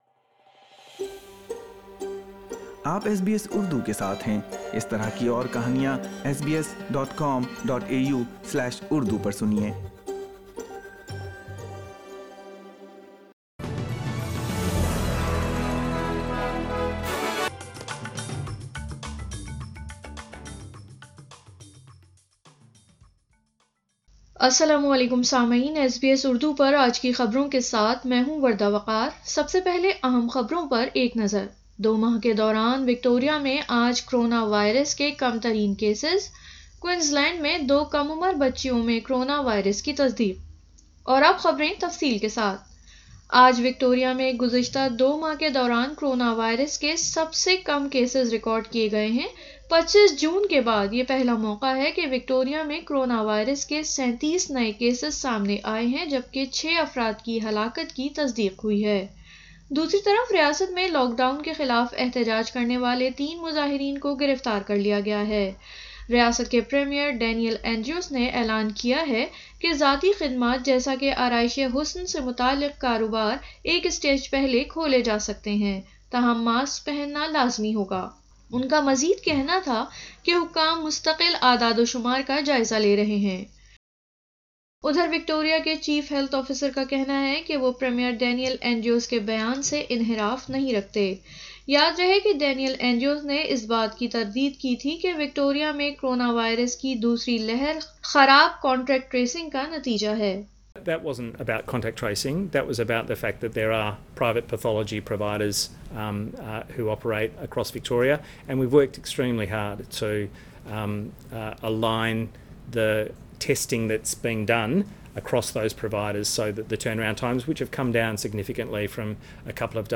اردو خبریں 12 ستمبر 2020